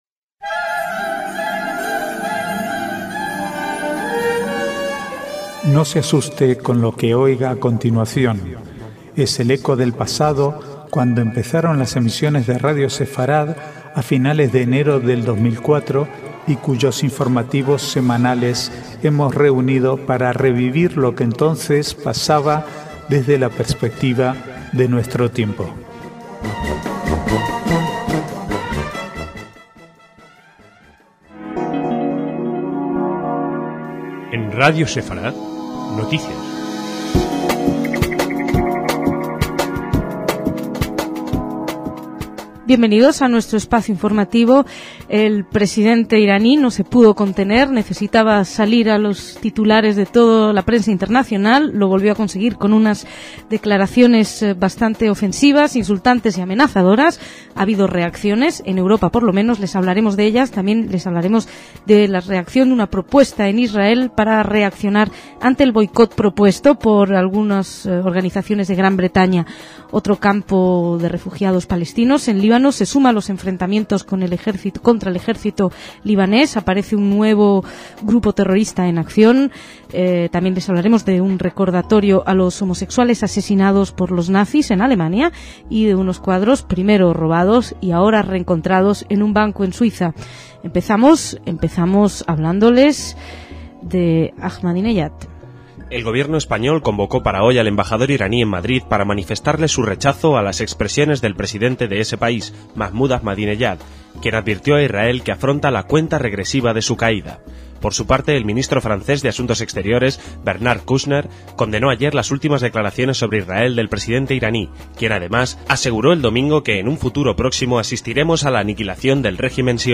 Archivo de noticias del 5 al 7/6/2007